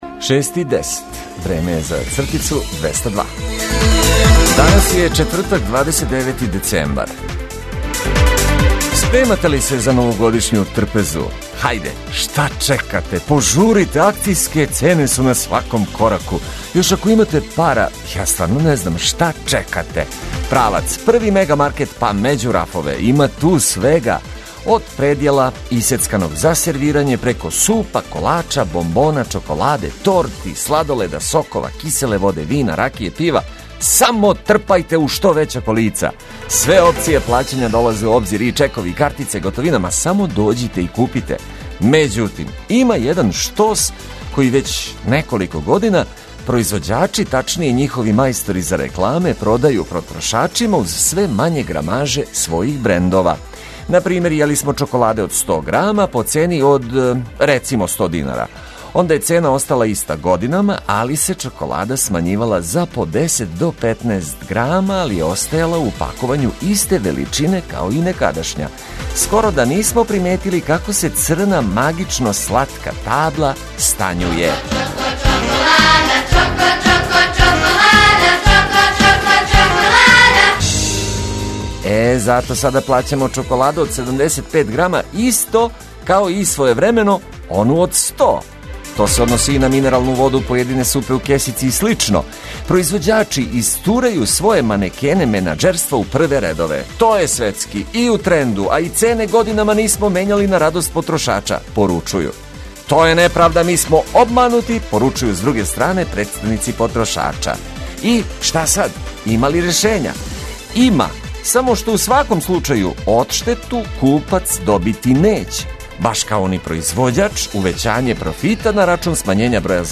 Овога јутра уз лепе и корисне приче, проткане ведром музиком, заједно са вама делимо почетак новог дана.